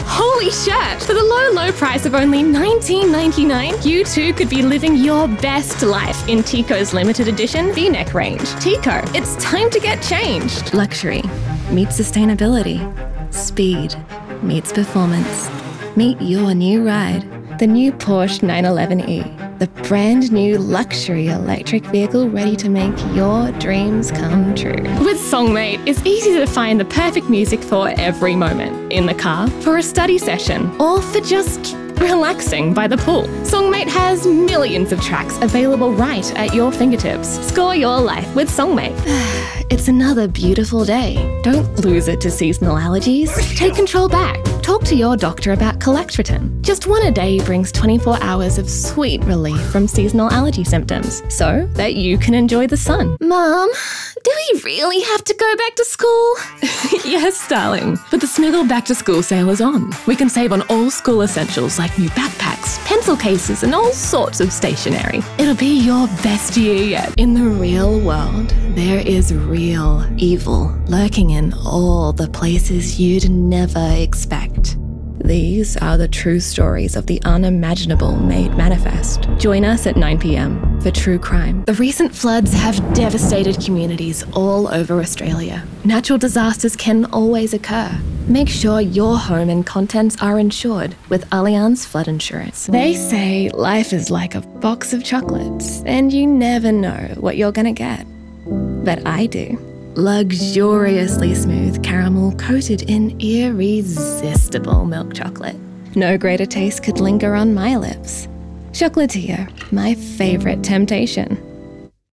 Best Female Voice Over Actors In April 2026